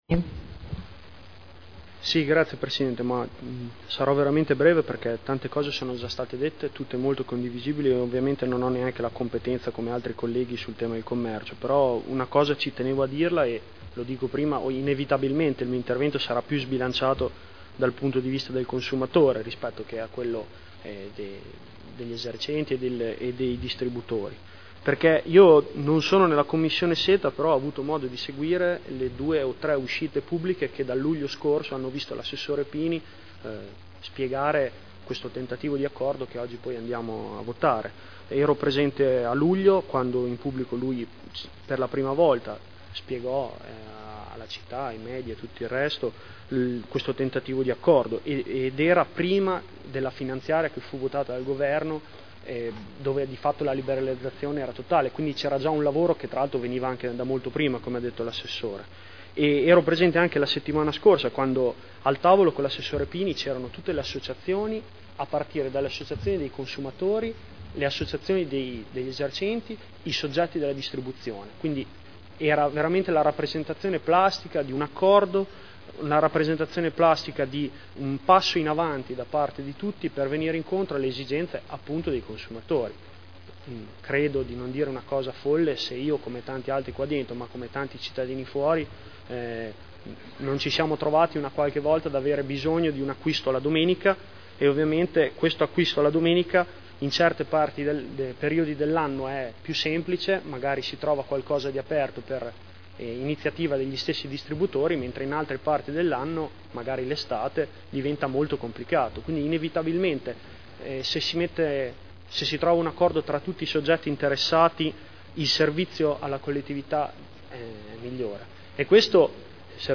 Giulio Guerzoni — Sito Audio Consiglio Comunale
Dibattito.